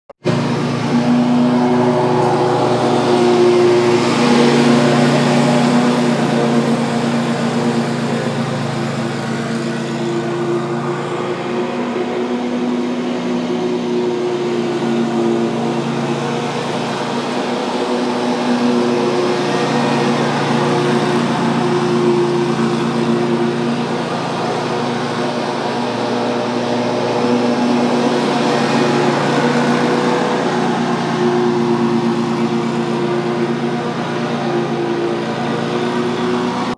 Lawnmower
lawnmower.m4a